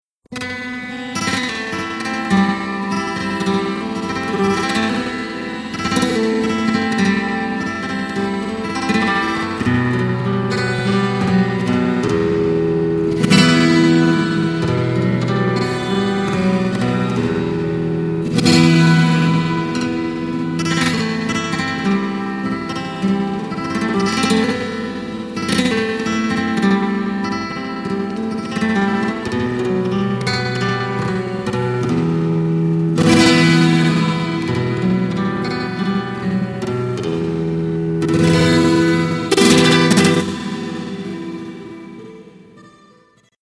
ソレアのファルセータを応用した、素晴らしいオリジナルフレーズだと思います。